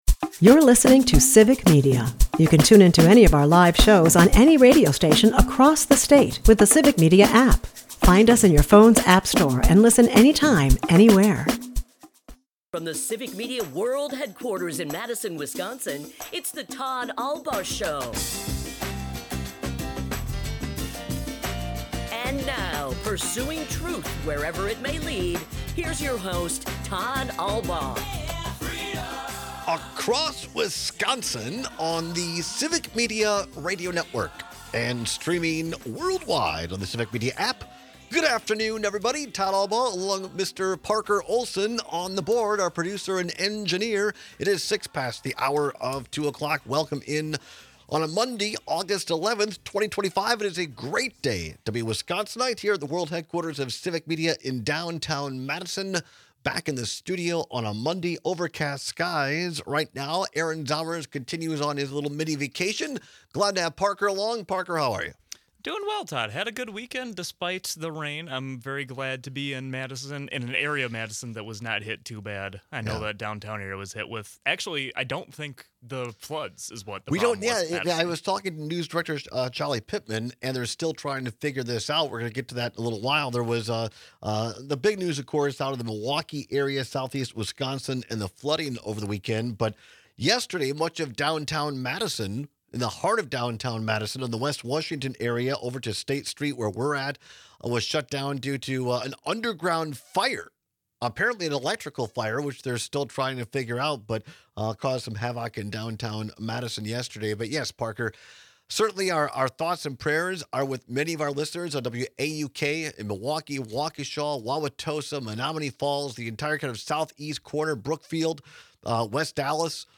radio network and airs live Monday through Friday from 2-4 pm across Wisconsin